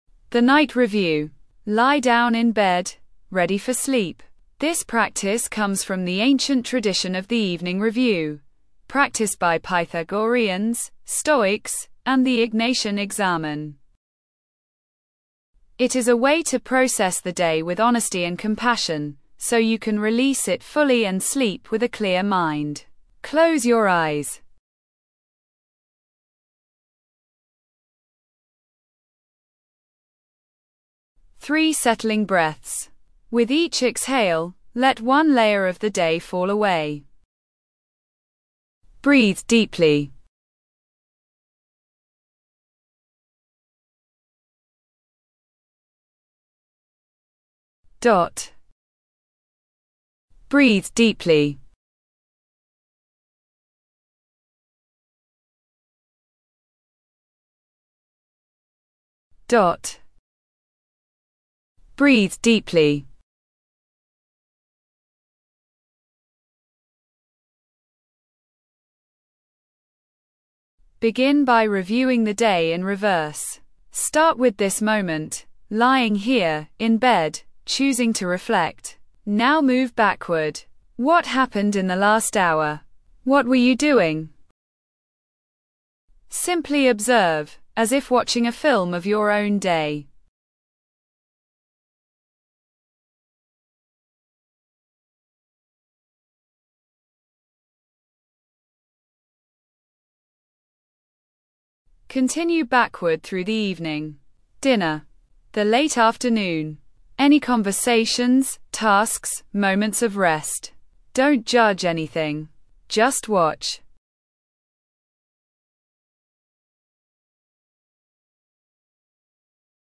The Night Review: An Evening Reflection and Sleep Preparation Meditation